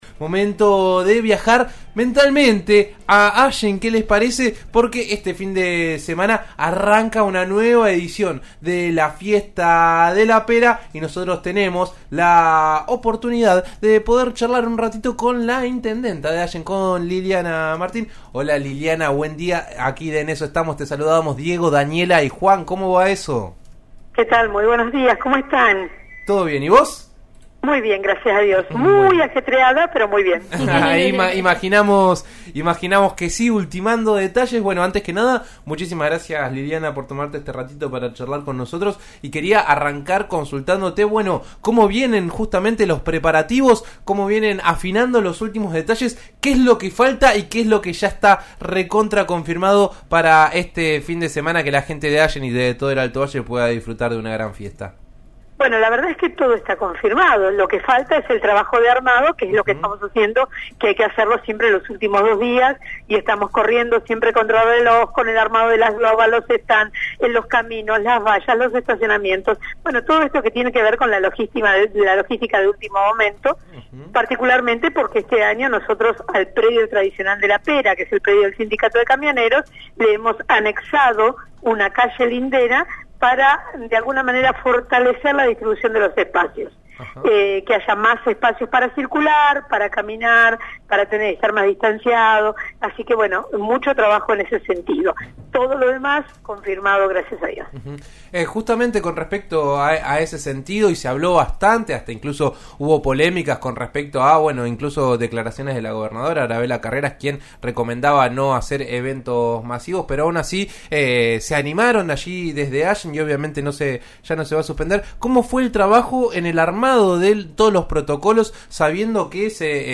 En eso estamos de RN Radio (89.3) dialogó con Liliana Martín, intendenta de Allen, para conocer los detalles de la Fiesta de la Pera, que se celebra este fin de semana en la región.